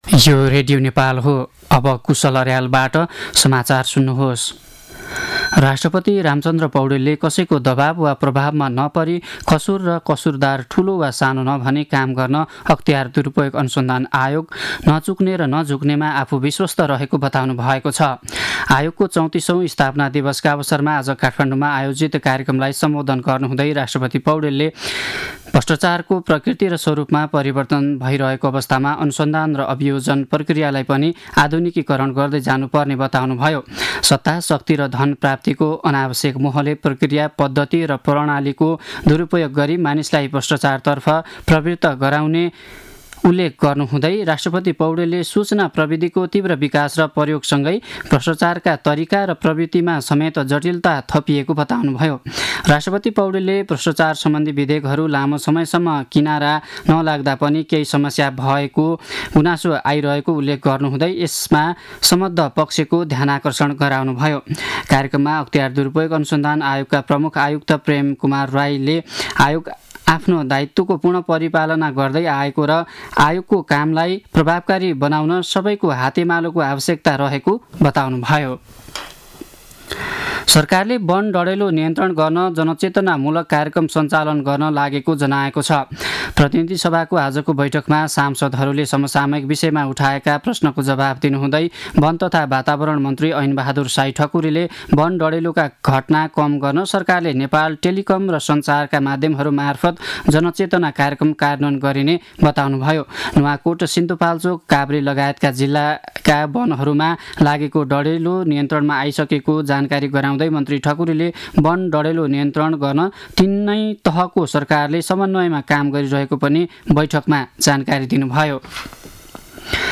दिउँसो ४ बजेको नेपाली समाचार : २९ माघ , २०८१
4-pm-news-1-3.mp3